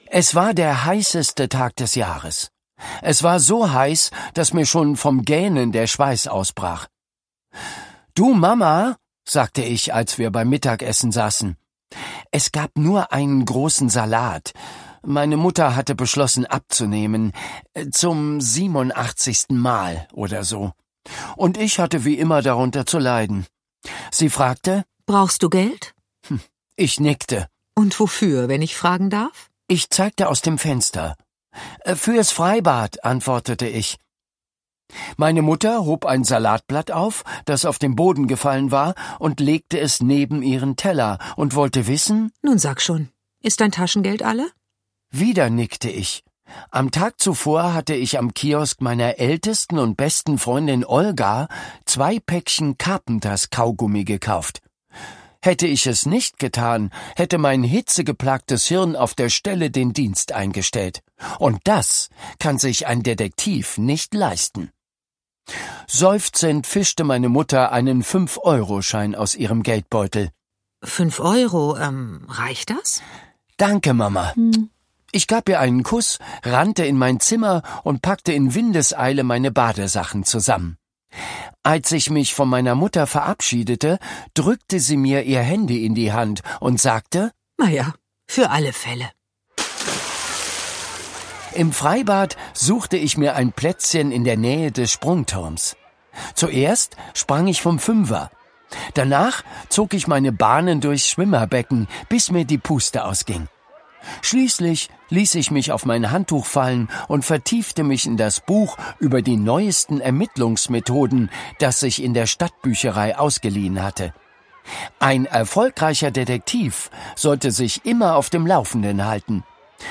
Schlagworte Detektivgeschichte • Detektivgeschichten; Kinder-/Jugendliteratur • Hörbuch; Lesung für Kinder/Jugendliche • Krimi • Krimis/Thriller; Kinder-/Jugendliteratur • Privatdetektiv